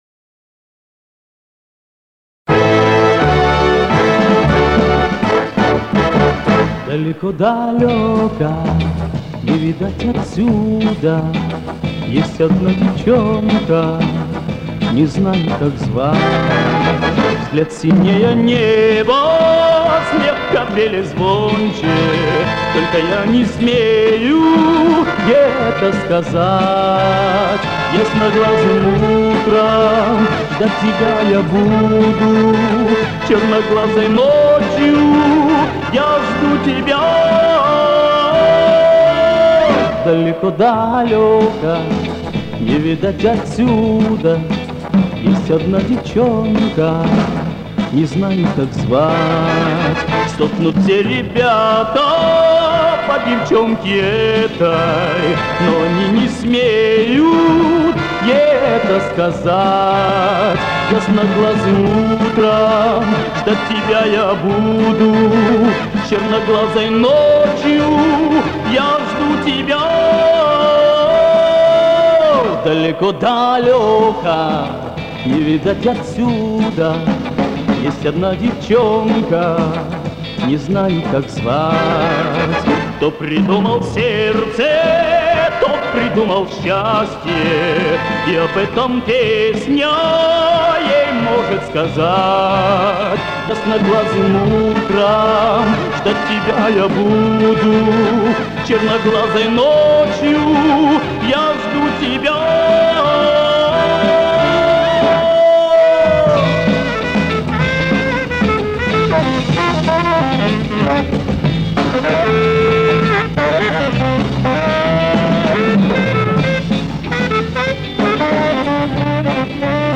Небольшая реставрация Вашего исходника.
Микропровалы почти полностью сгладились и хрипы исчезли.